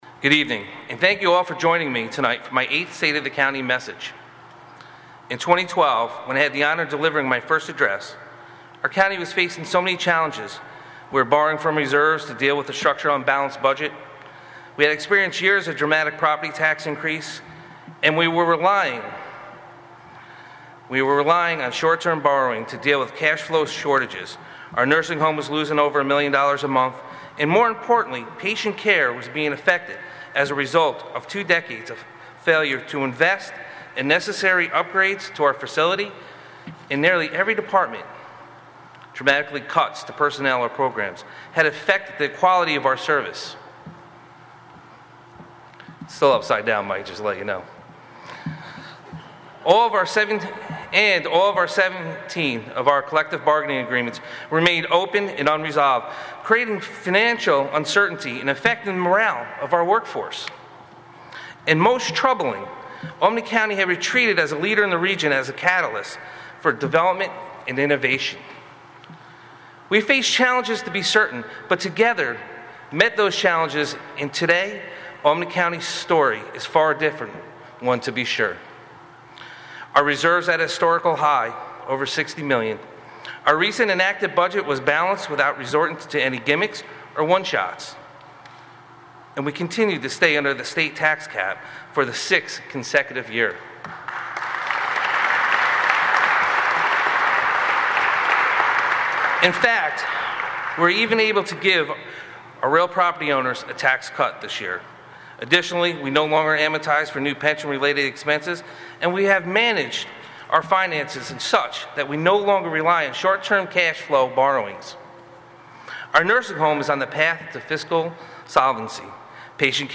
Albany County Executive McCoy Delivers 'State Of The County' Address